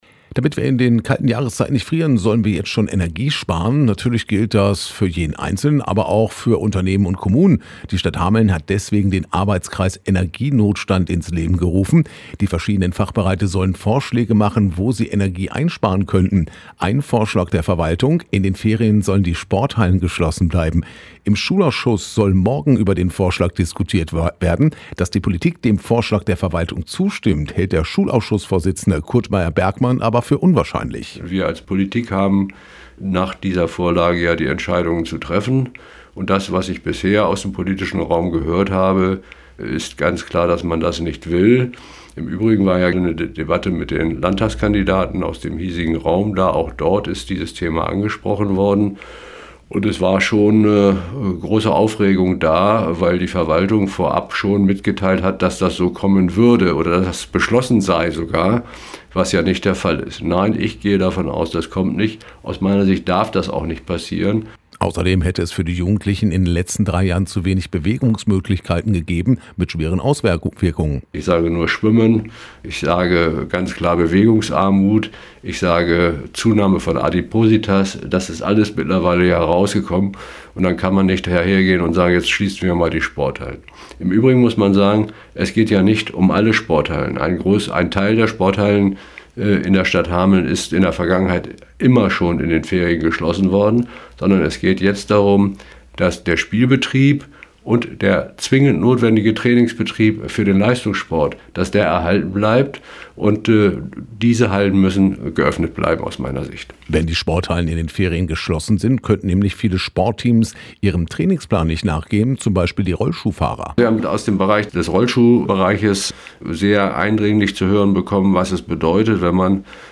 Aktuelle Lokalbeiträge Hameln: STADT WILL SPORTHALLEN SCHLIESSEN Play Episode Pause Episode Mute/Unmute Episode Rewind 10 Seconds 1x Fast Forward 30 seconds 00:00 / Download file | Play in new window Schulausschussvorsitzender Kurt Meyer-Bergmann, zu dem Vorschlag der Verwaltung einige Sporthallen während der Ferien zu schließen, um Energie zu sparen…